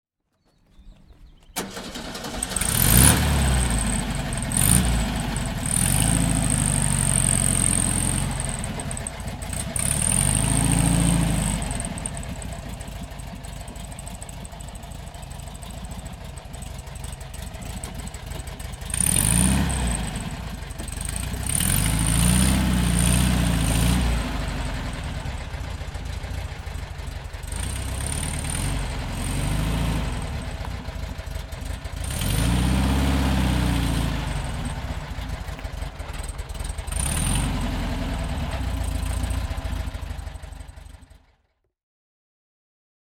VW Beutler Coupé (1958) - The revised engineVW Beutler Coupé (1958) - The revised engine
VW Beutler Coupé (1958) - Starten und Leerlauf